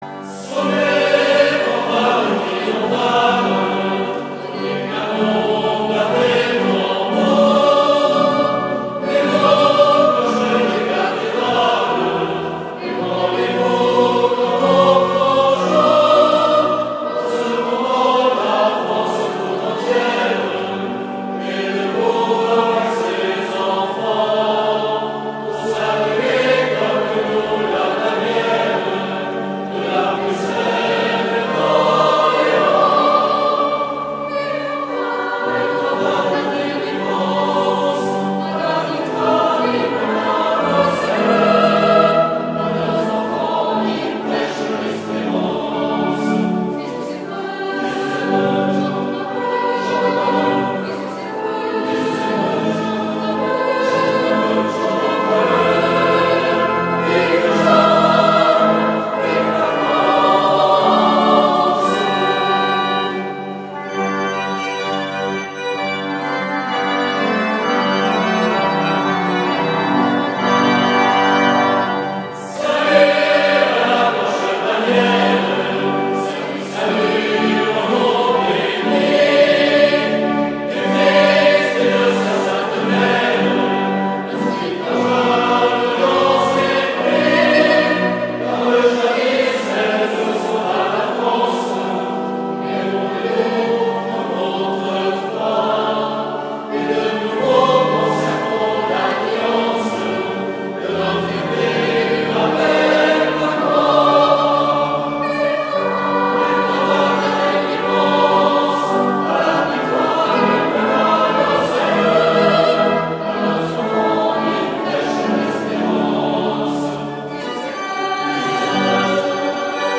Sortie  Cantate à sainte Jeanne d'Arc J14
Lieu Eglise Saint-François-de-Paule (Fréjus)